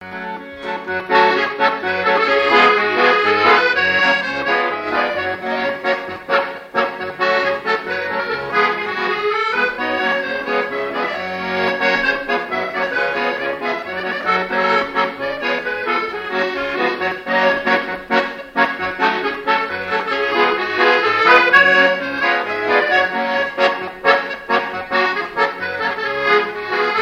danse : paso-doble
Pièce musicale inédite